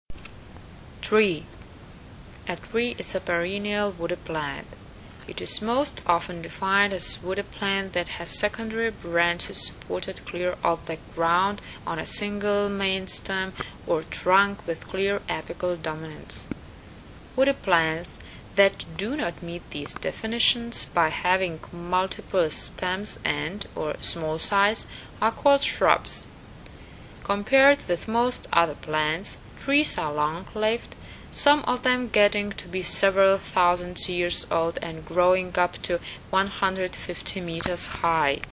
Nahrávka výslovnosti (*.MP3 soubor):